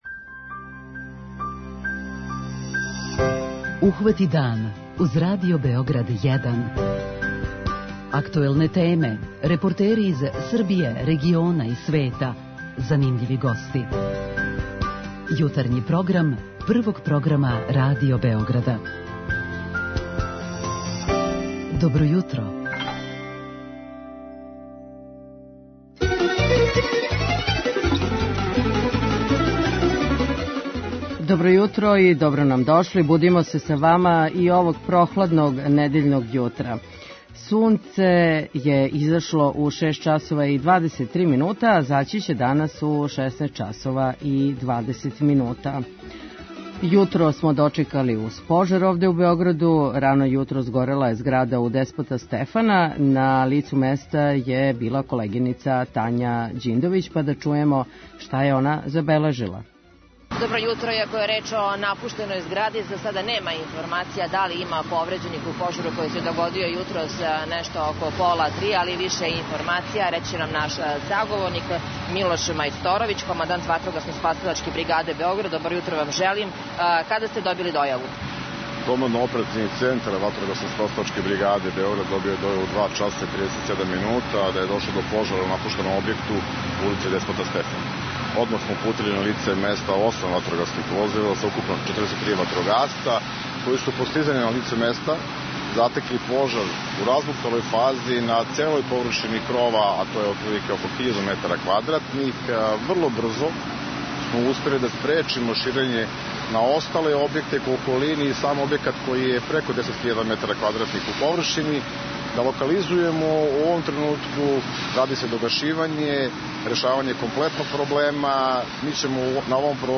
преузми : 16.17 MB Ухвати дан Autor: Група аутора Јутарњи програм Радио Београда 1!